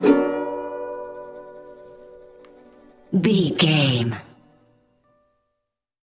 Uplifting
Ionian/Major
D
acoustic guitar
bass guitar
ukulele